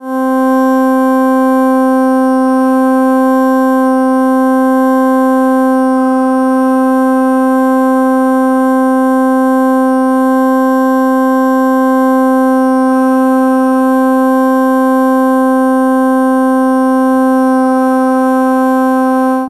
Ronqueta de gaita gallega en escala diatónica tocando la nota C
roncón
gaita